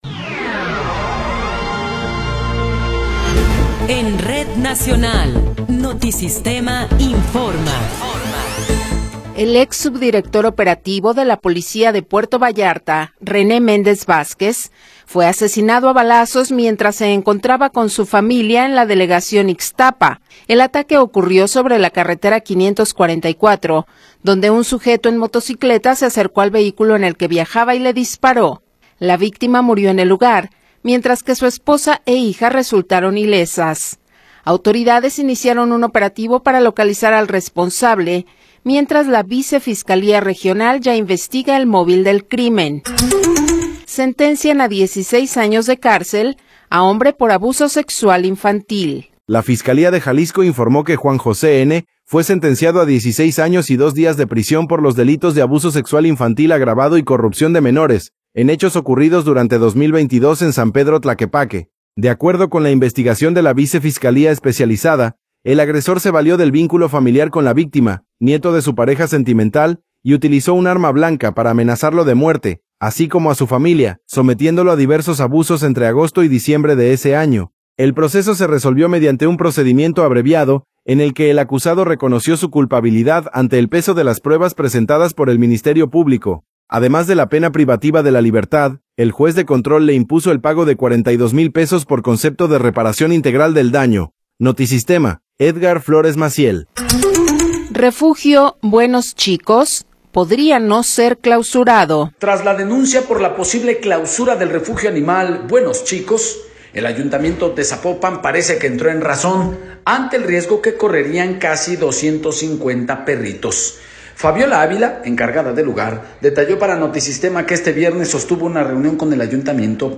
Noticiero 15 hrs. – 17 de Abril de 2026
Resumen informativo Notisistema, la mejor y más completa información cada hora en la hora.